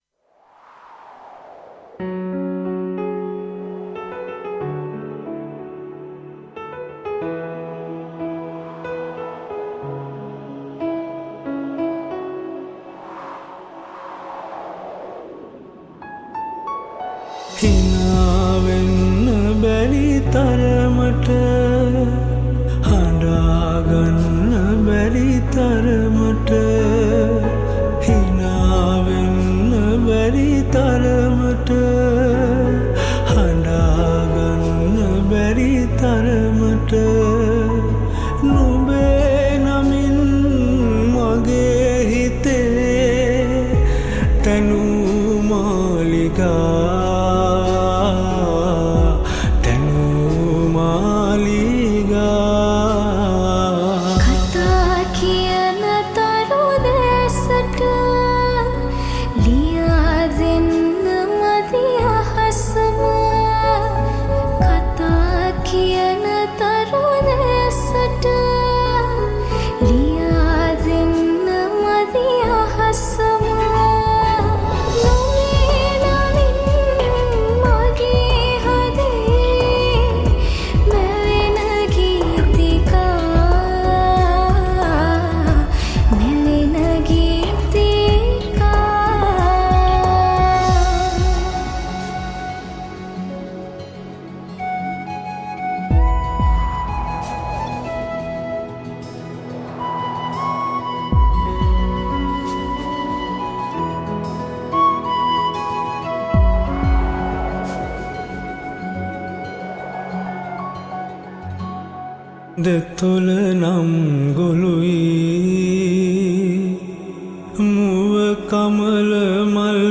Cover